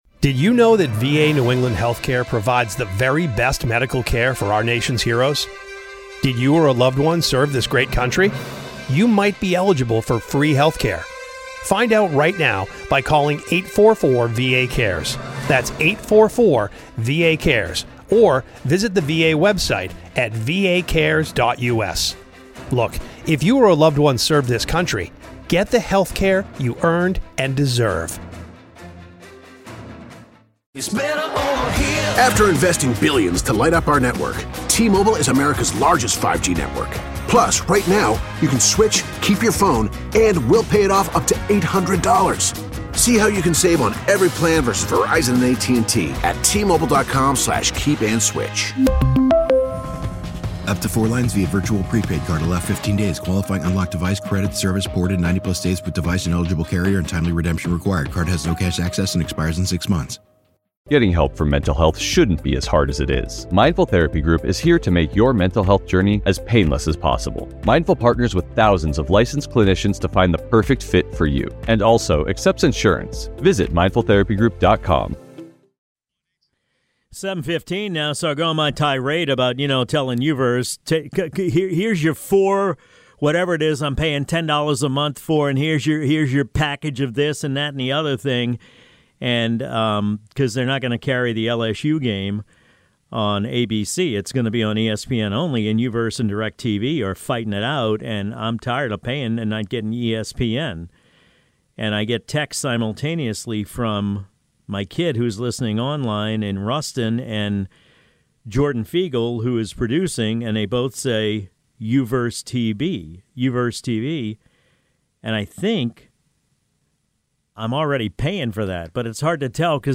talks with Insurance Commissioner Tim Temple